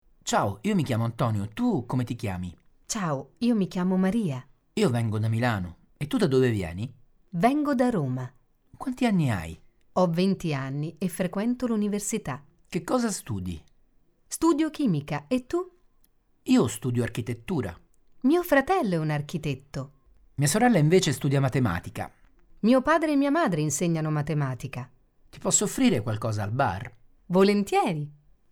dialogo su all’università